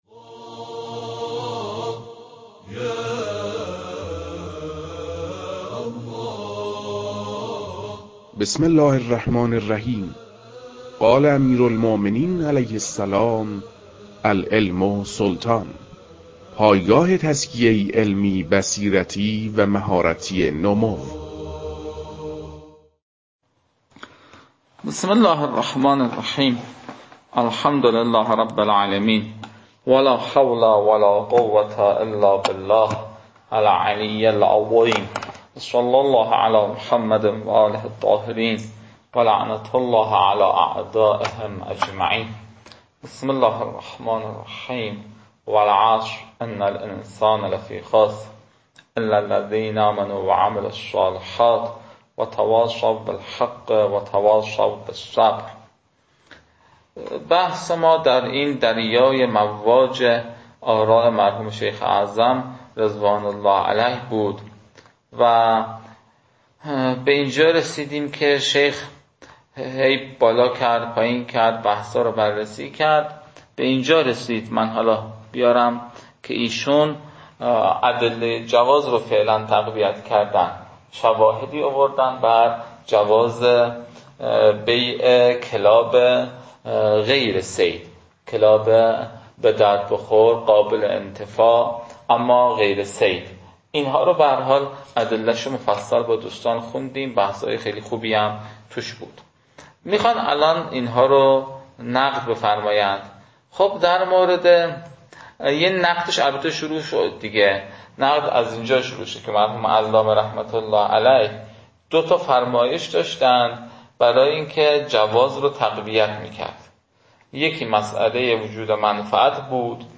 فایل های مربوط به تدریس بخش نخست كتاب المكاسب